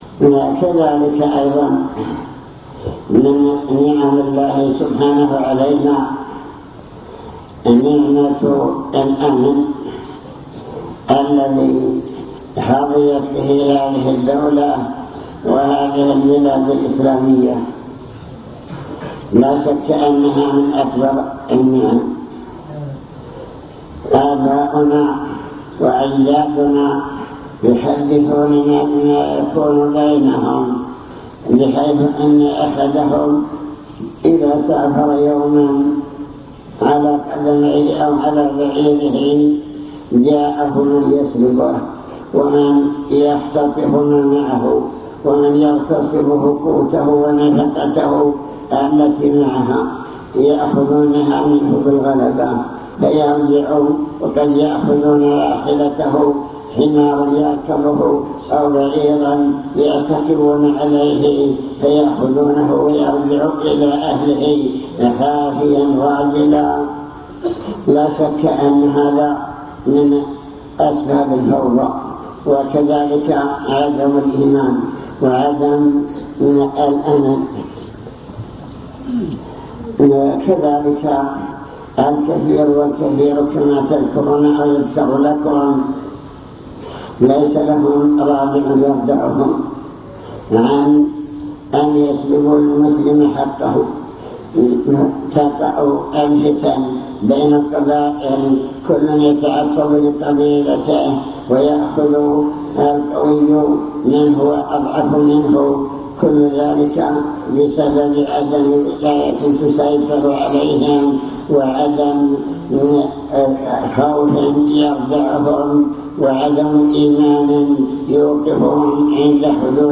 المكتبة الصوتية  تسجيلات - محاضرات ودروس  محاضرة بعنوان شكر النعم (3) نماذج من نعم الله تعالى التي خص بها أهل الجزيرة